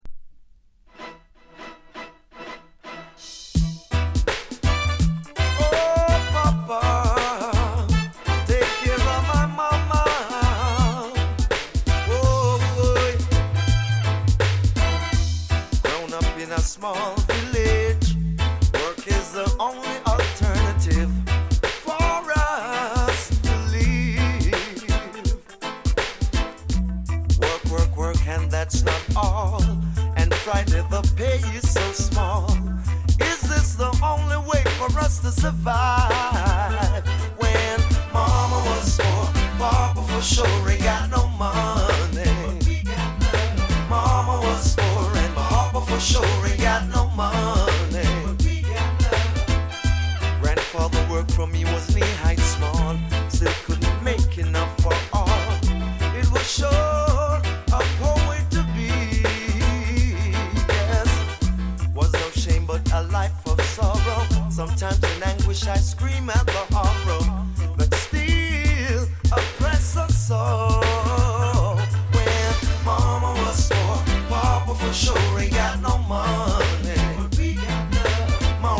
高品質 90s 唄もの *